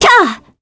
Scarlet-vox-Landing_kr.wav